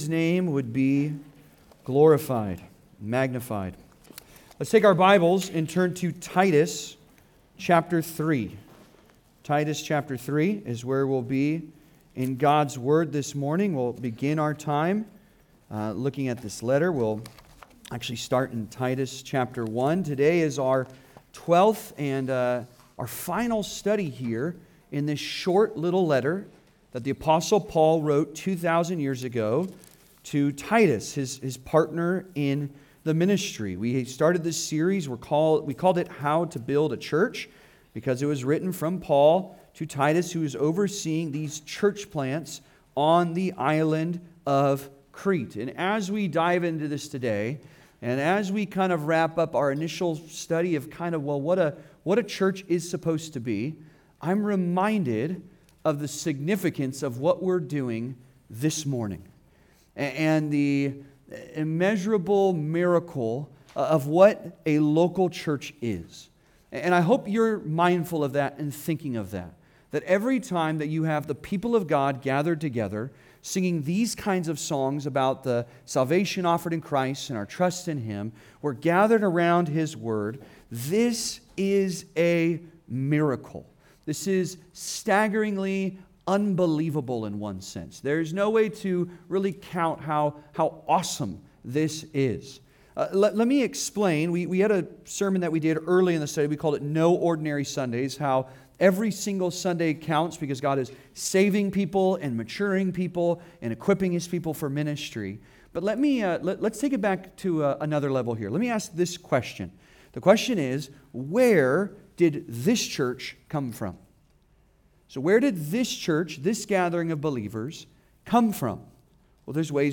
Final Priorities for a Gospel Church (Sermon) - Compass Bible Church Long Beach